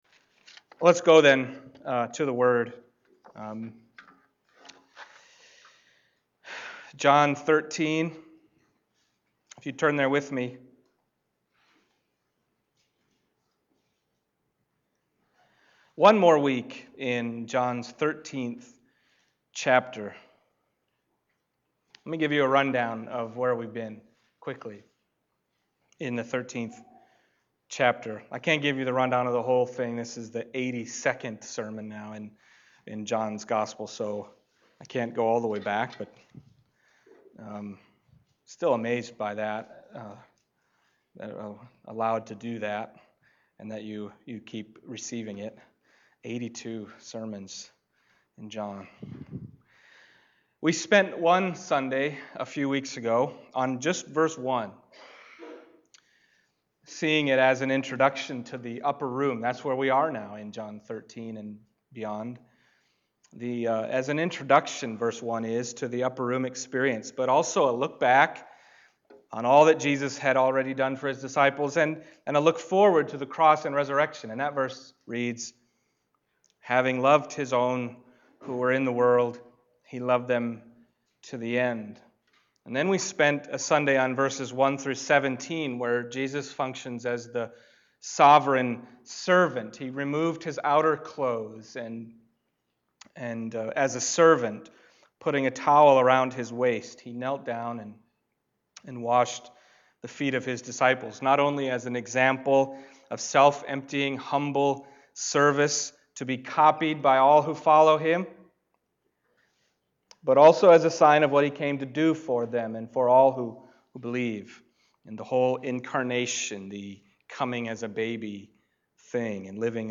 John 13:31-35 Service Type: Sunday Morning John 13:31-35 « Betraying and Denying Jesus The Word Made Flesh